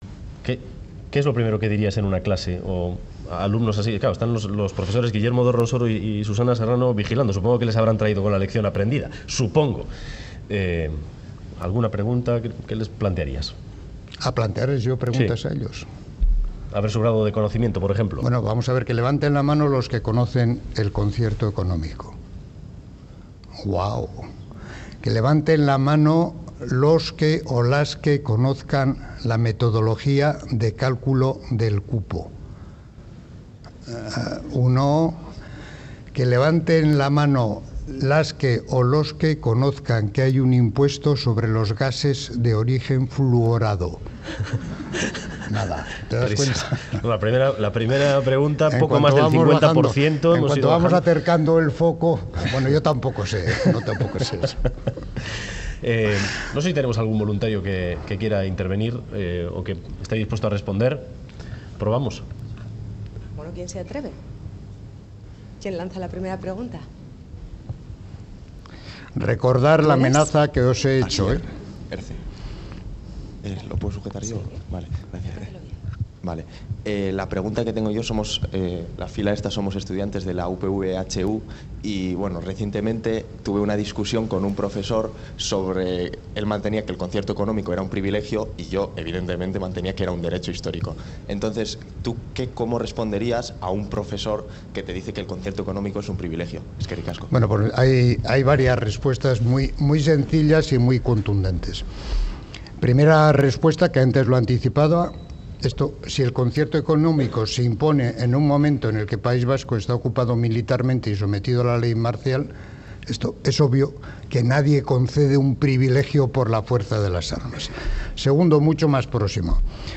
Audio: Especial 'Boulevard' desde el Museo de Bellas Artes de Bilbao, en el que Pedro Luis Uriarte ha dado una clase magistral sobre el Concierto Económico a los alumnos allí presentes.